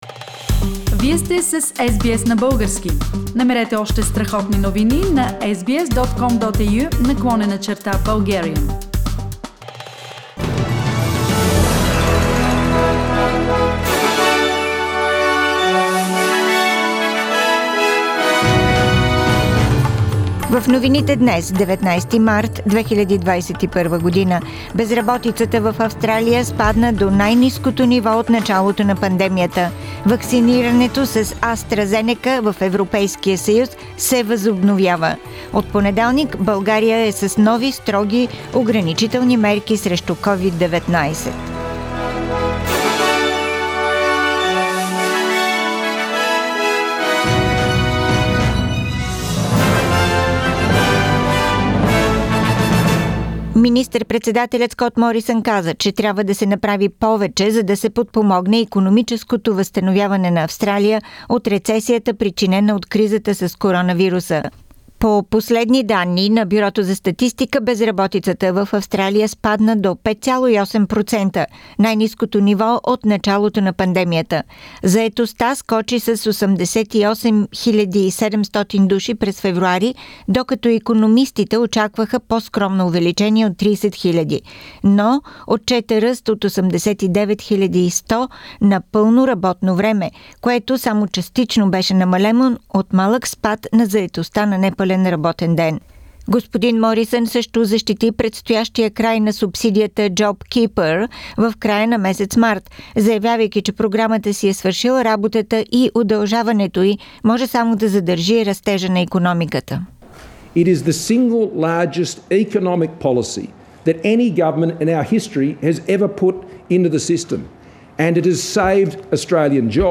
Weekly Bulgarian News – 19th March 2021